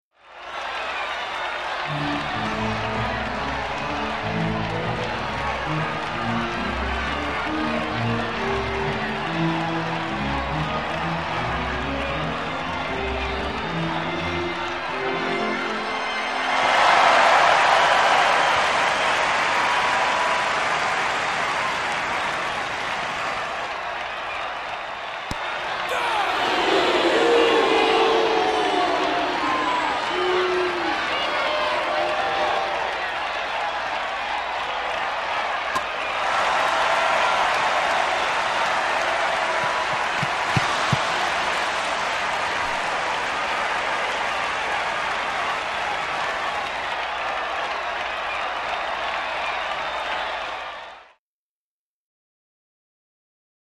Baseball Game | Sneak On The Lot
Baseball Game Scene With Organ, Crowd And Game Activity.